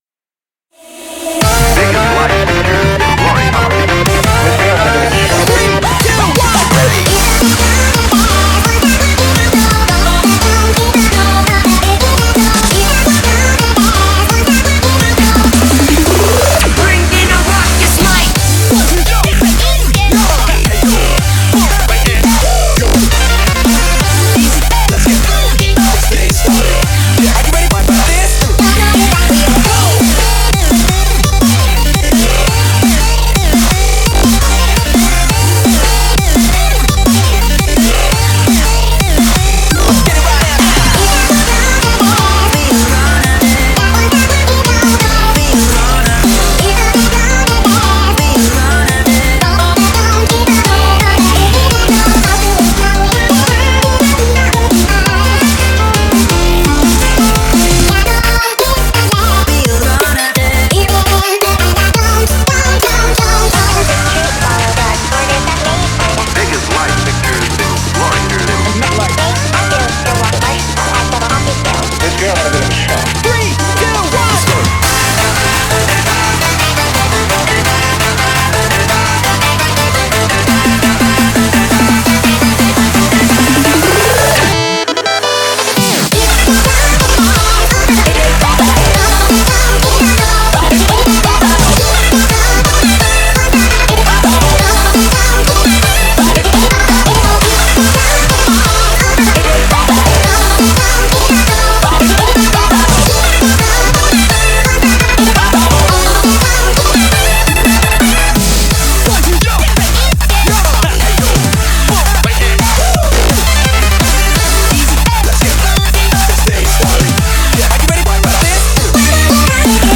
BPM85-170
Audio QualityCut From Video